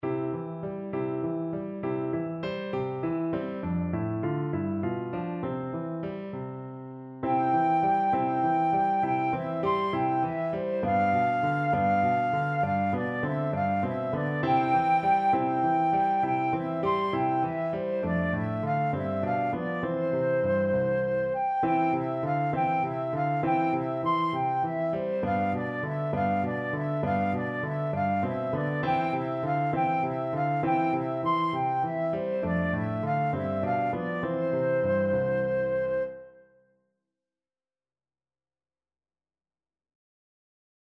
Flute
6/8 (View more 6/8 Music)
C major (Sounding Pitch) (View more C major Music for Flute )
Moderato
Traditional (View more Traditional Flute Music)